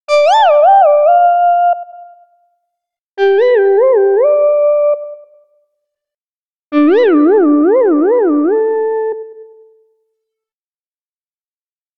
04697 sweeping messages dings
ding info message notification sound sweeping tone sound effect free sound royalty free Sound Effects